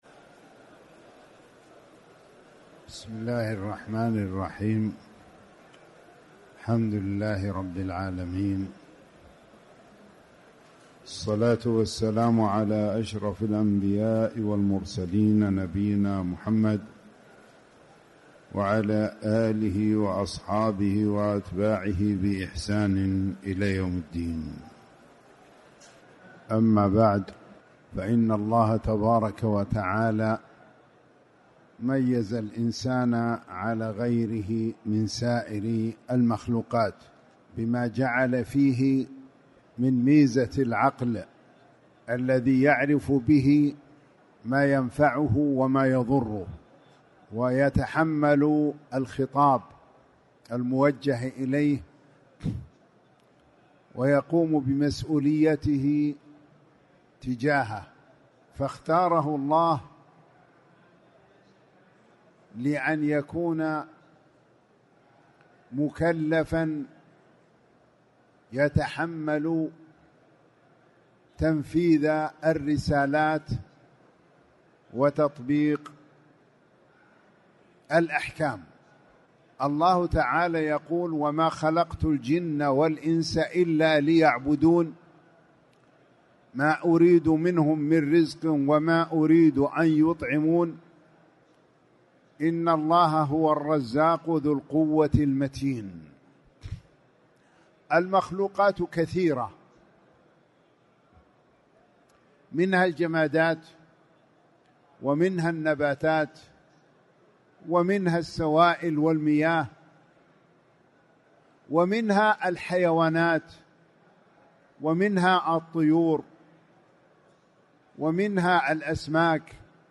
تاريخ النشر ٧ رمضان ١٤٣٨ هـ المكان: المسجد الحرام الشيخ